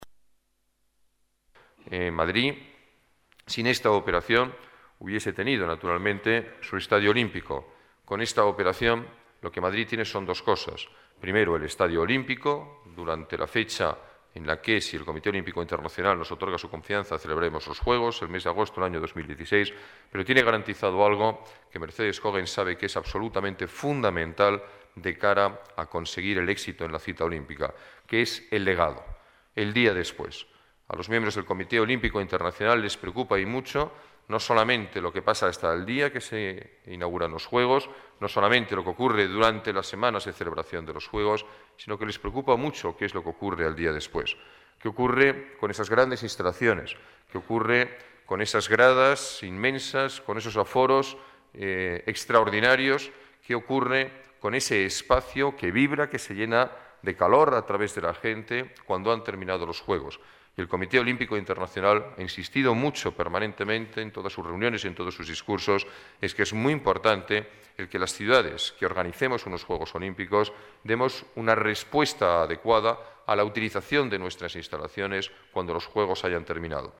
Nueva ventana:Declaraciones alcalde, Alberto Ruiz-Gallardón: convenio Atlético de Madrid, nuevo estadio